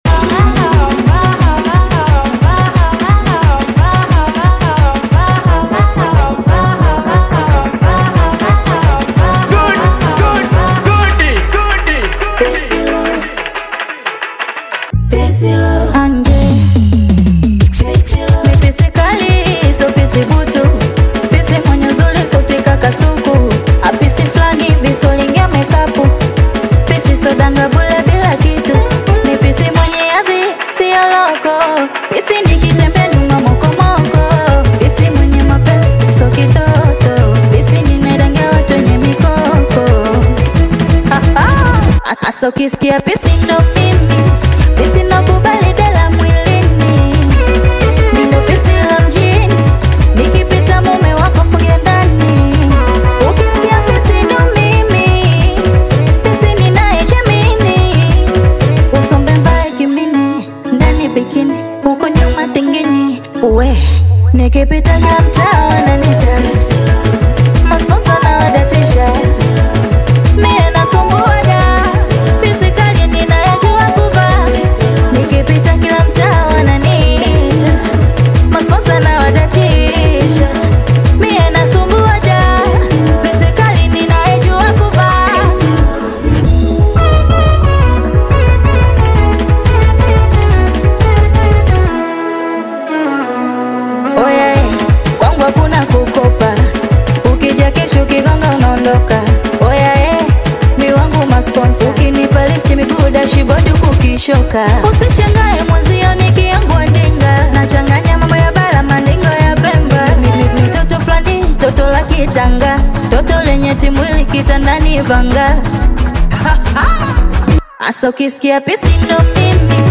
Bongo Flava
catchy beats
soulful voice